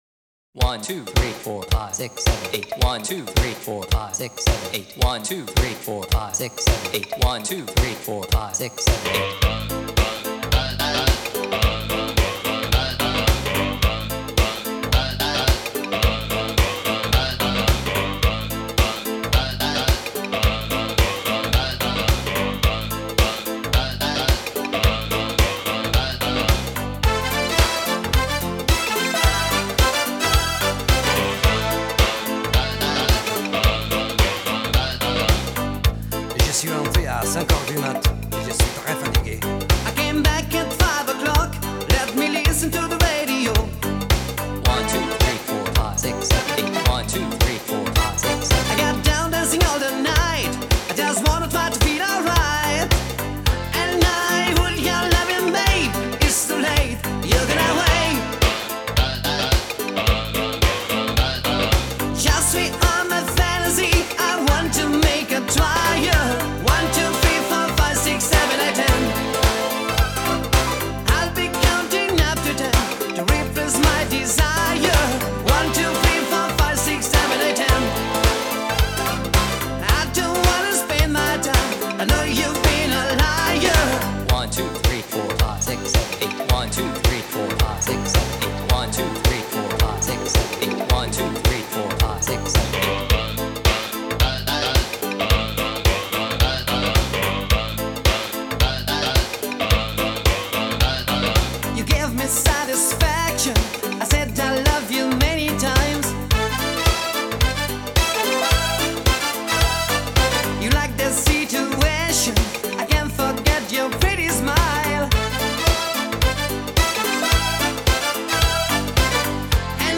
- это уже классический евробит